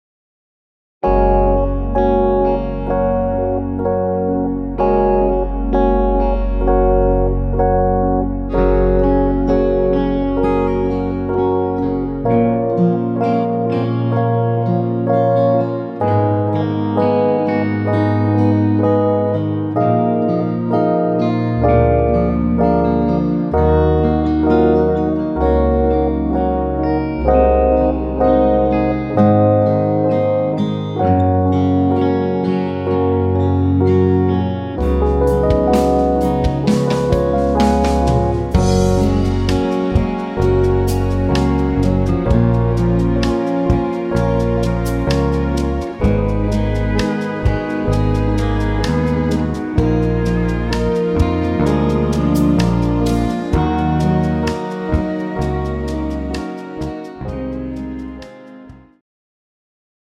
pop-rock ballad style
tempo 64 bpm
female backing track
This backing track is in pop rock ballad style.